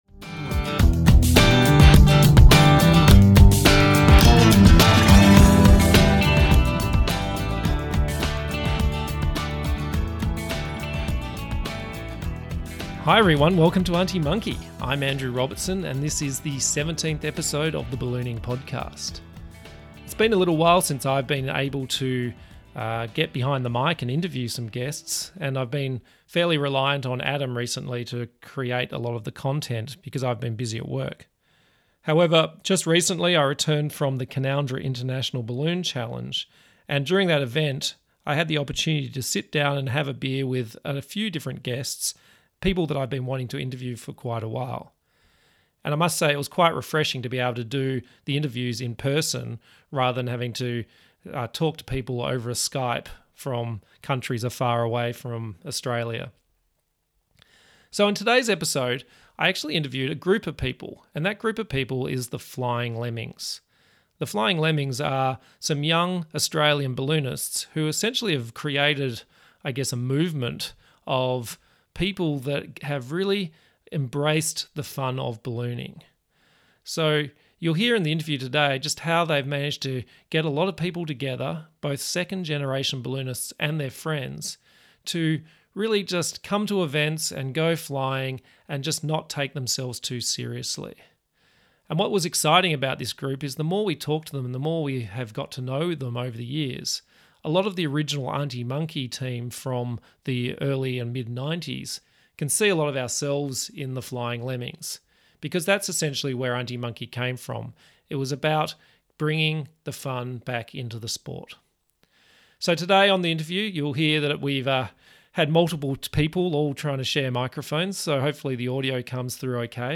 A bunch of self-indulgent millennials flying balloons and mucking around